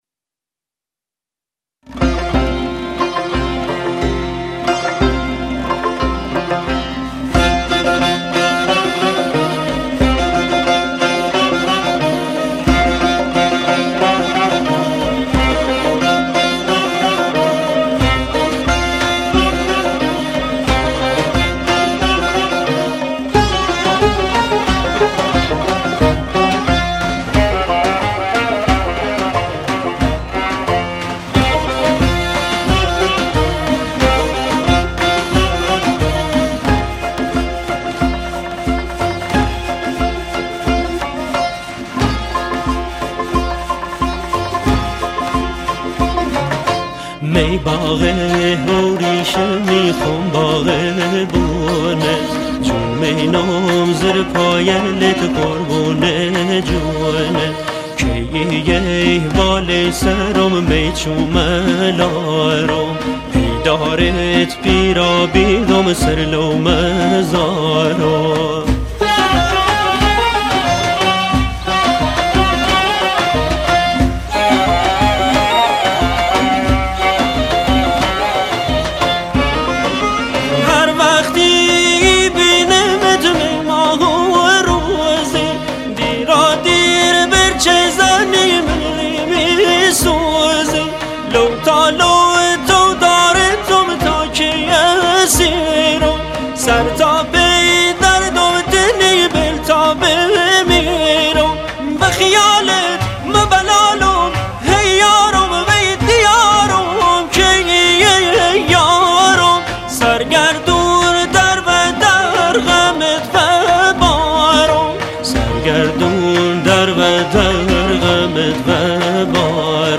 یک آهنگ لری شاد و فولکوریک